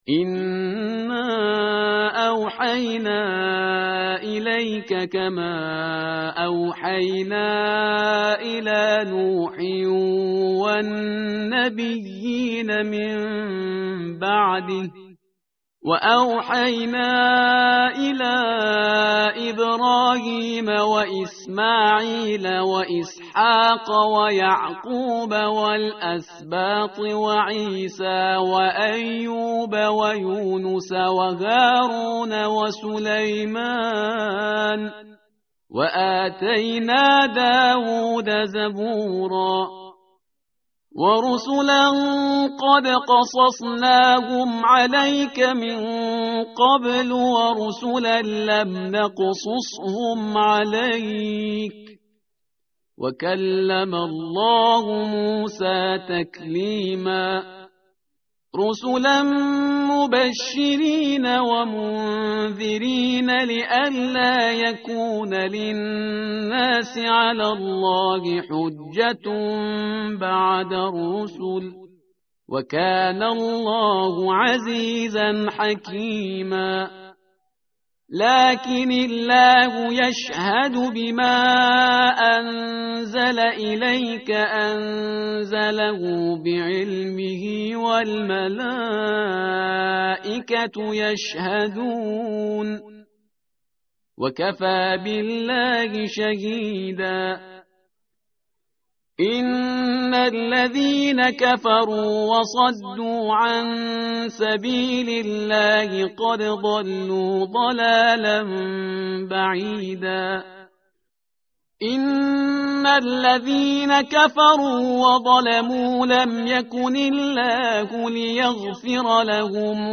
متن قرآن همراه باتلاوت قرآن و ترجمه
tartil_parhizgar_page_104.mp3